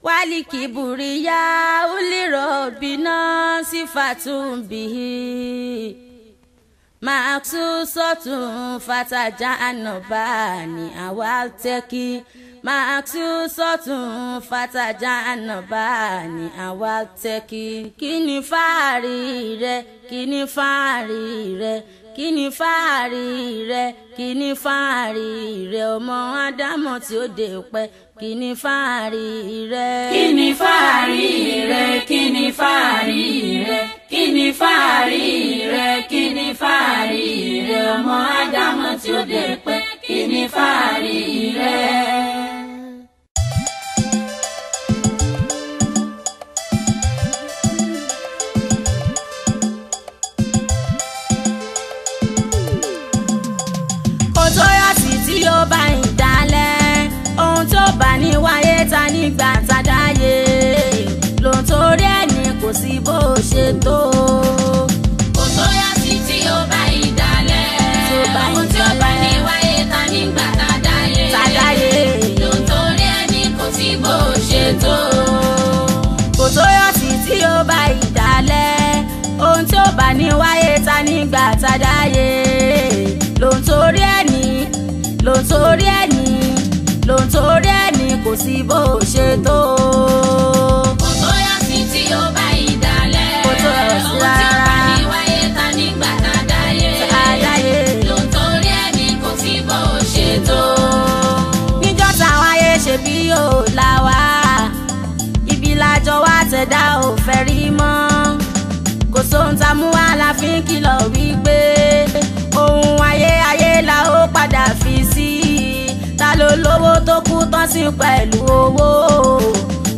Nigerian Yoruba Fuji track
Yoruba Islamic song